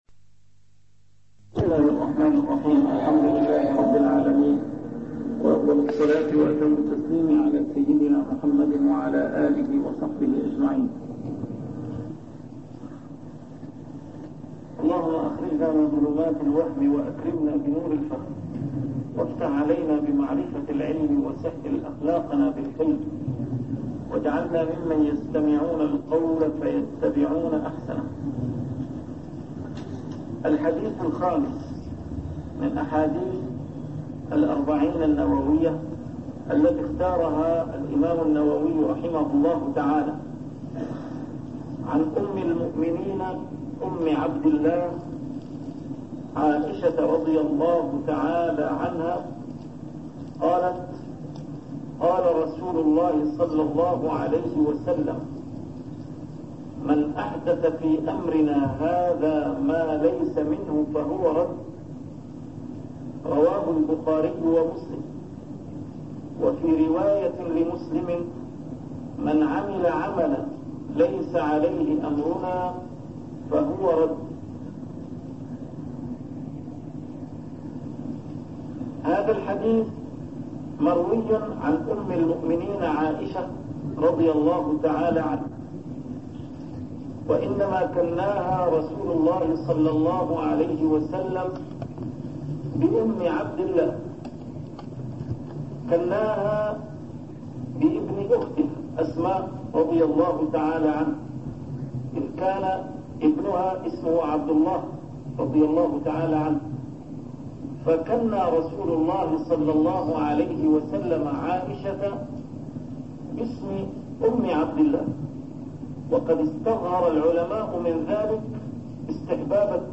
شرح الأحاديث الأربعين النووية - A MARTYR SCHOLAR: IMAM MUHAMMAD SAEED RAMADAN AL-BOUTI - الدروس العلمية - علوم الحديث الشريف - شرح الحديث الخامس: حديث عائشة (مَنْ أحدث من أمرنا هذا ما ليس من فهو رد) 23